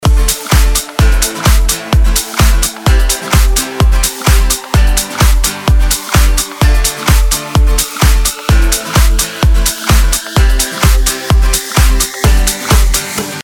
Нужна такая бочка